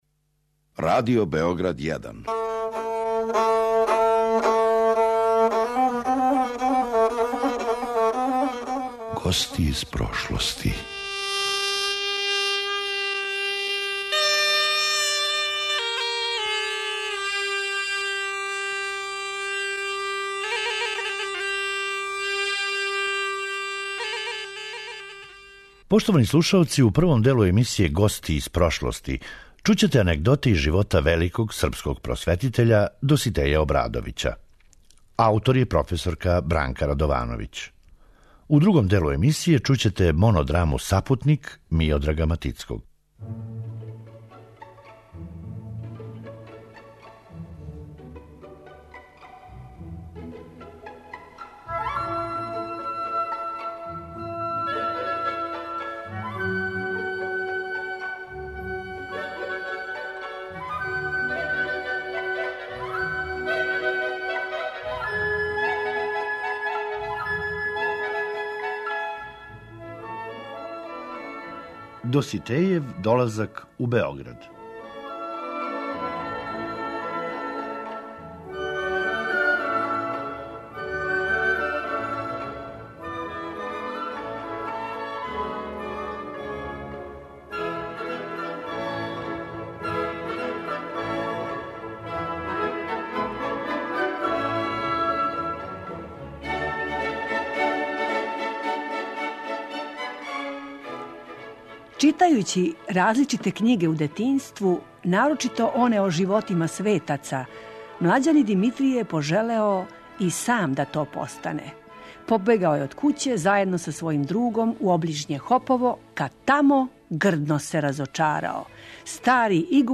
монодраму о Доситејевом животу „Сапутник" Миодрага Матицког